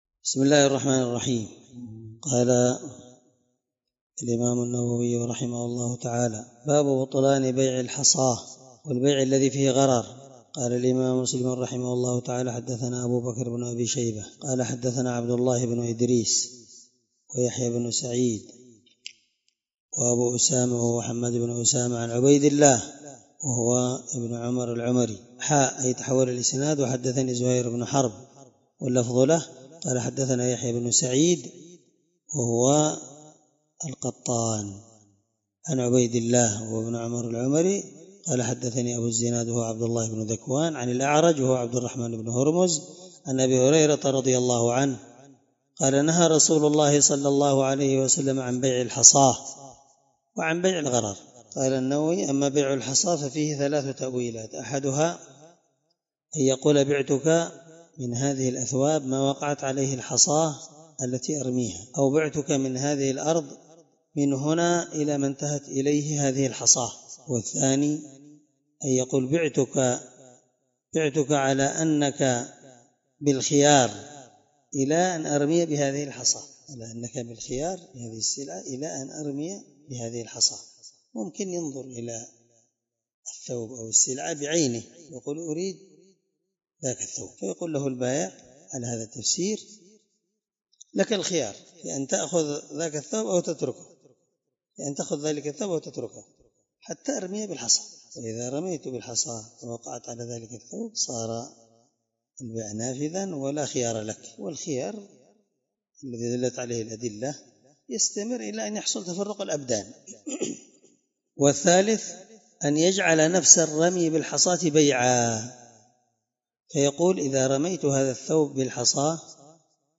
الدرس2من شرح كتاب البيوع حديث رقم(1513) من صحيح مسلم